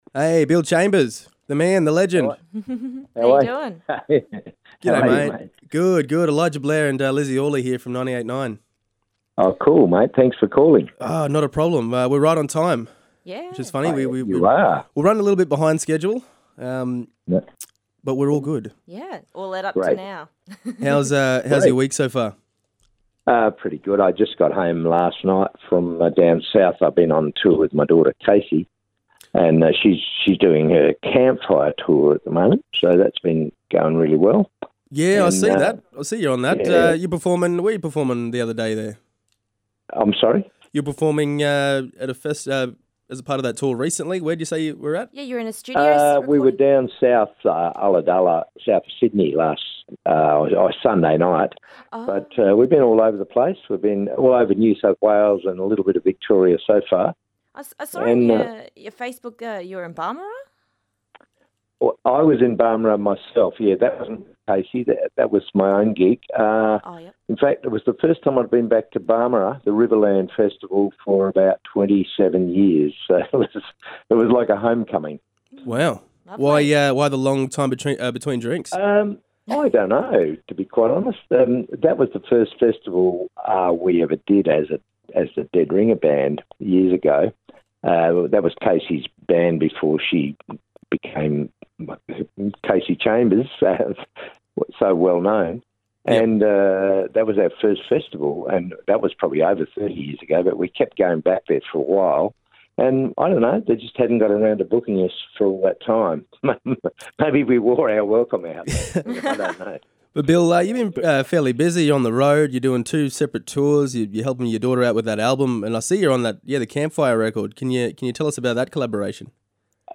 Bill Chambers call into yarn all things country music, with a particular focus on the Groundwater gig locked in for late July (27-29th).
billchambersinterview.mp3